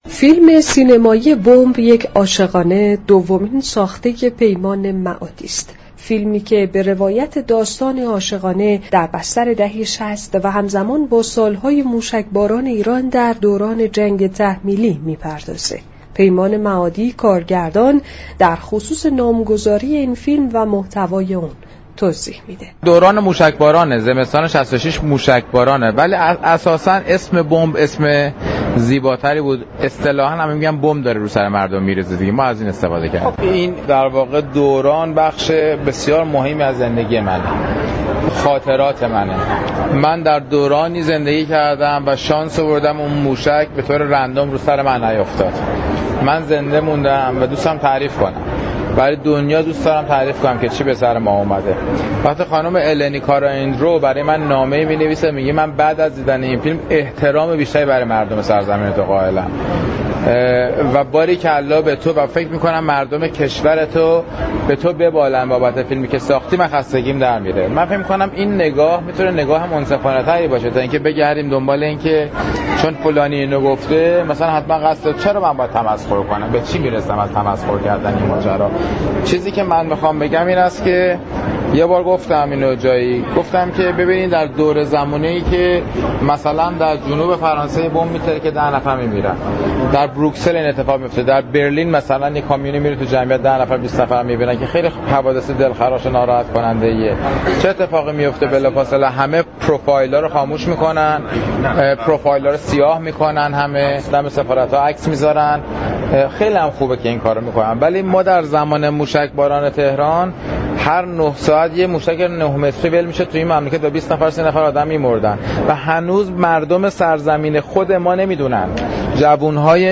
گزارشی از فیلم سینمایی بمب، یك عاشقانه